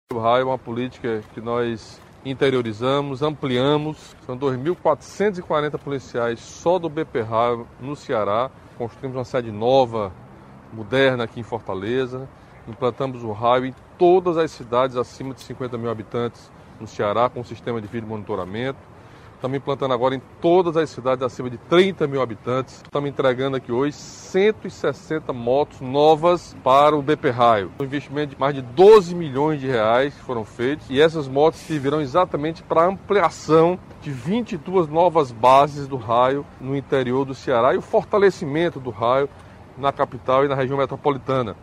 O governador Camilo Santana destacou a entrega dos novos equipamentos para o CPRAIO.